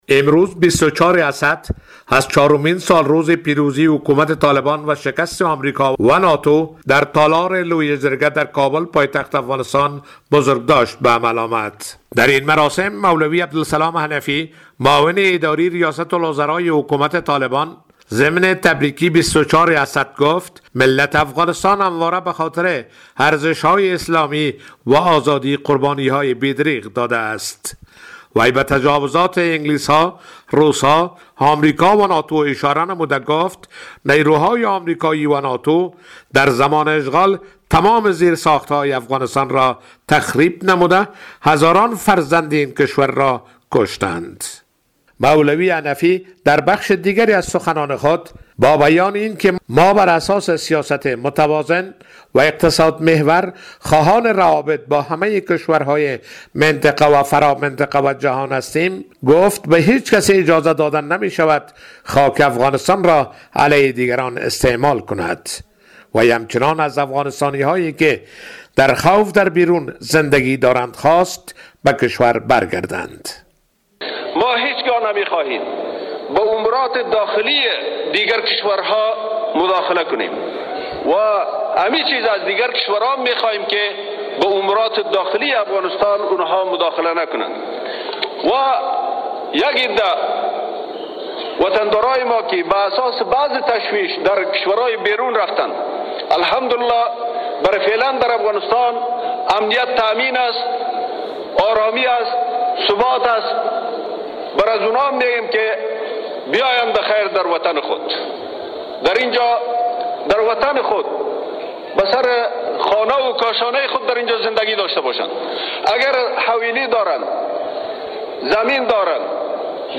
از ۲۴اسد، چهارمین سالروز پیروزی حکومت طالبان و شکست آمریکا و ناتو در تالار لویه جرگه در کابل پایتخت افغانستان با تدابیر شدید امنیتی بزرگداشت به عمل آمد.